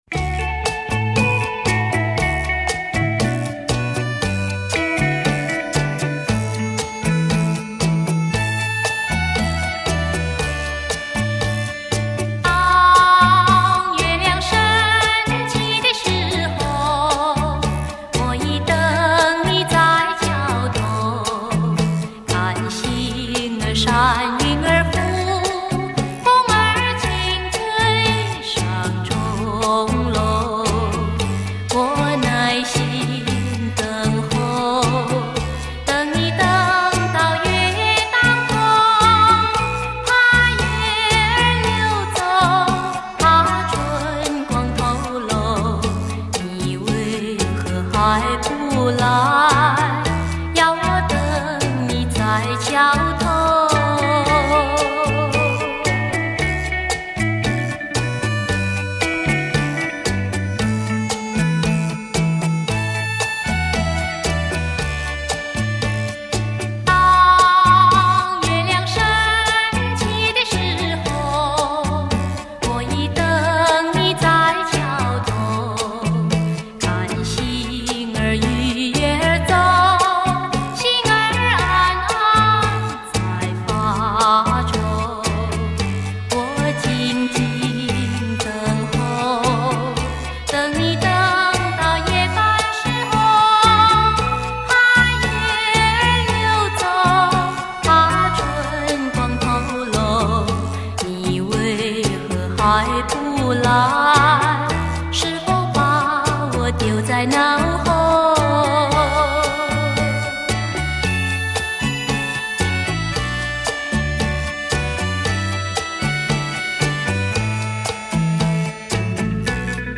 发音吐字清晰、准确，以及对歌曲诠释的理解和把握都十分到位